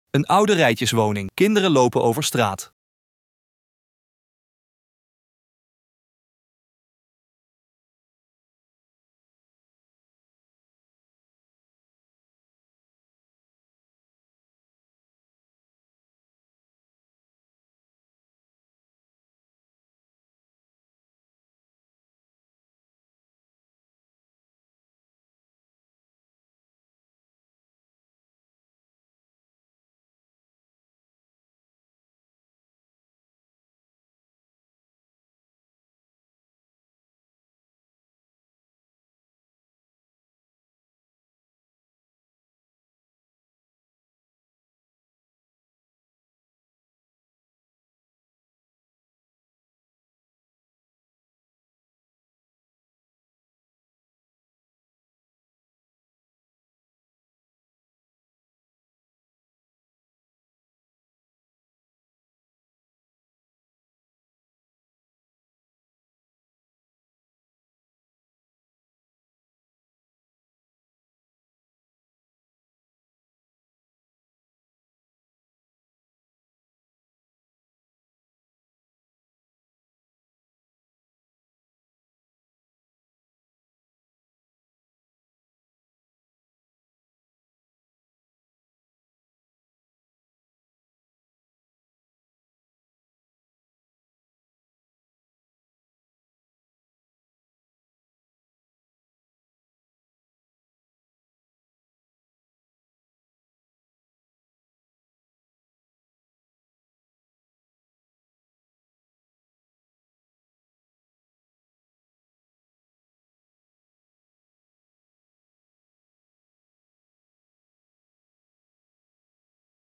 Stadsdeelbestuurder Yassmine el Ksaihi vertelt hoe ze deze complexe opgave aanpakken in een buurt die niet allen fysieke, maar ook sociale uitdagingen kent.